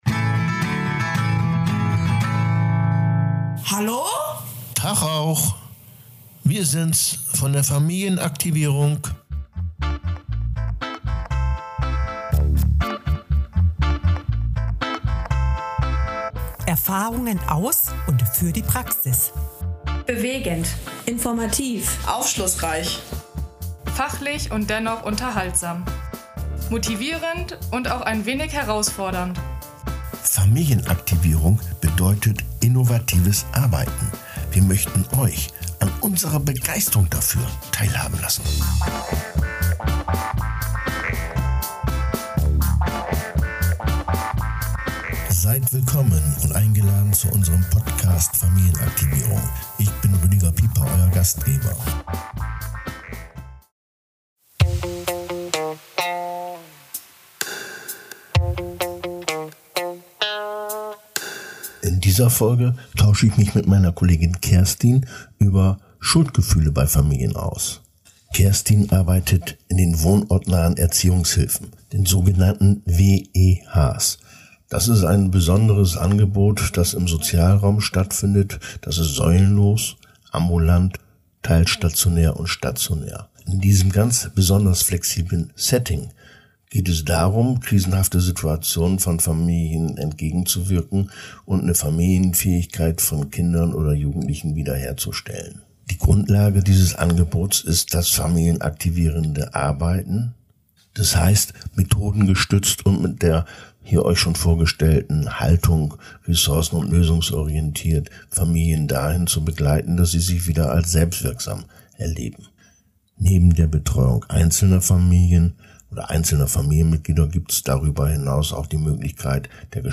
Ein Brief einer Mutter dient als Anlass für ein Gespräch von Fachkräften über den Umgang mit Schuldgefühlen und selbstschädigende Gedanken. Was lösen sie bei Familienmitgliedern und Fachkräften aus?